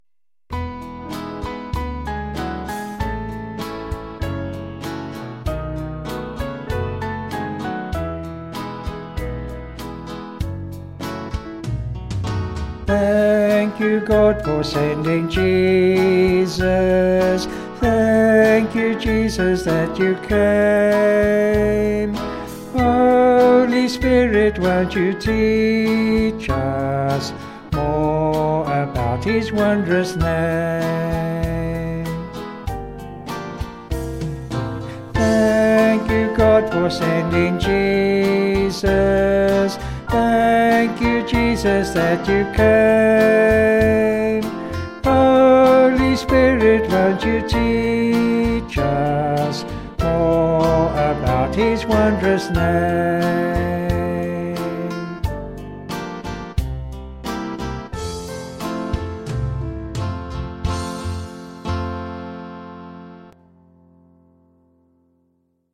Vocals and Band   264.2kb